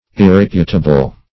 Meaning of irreputable. irreputable synonyms, pronunciation, spelling and more from Free Dictionary.
Irreputable \Ir*rep"u*ta*ble\, a.